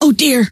Index of /sc/sound/scientist_female/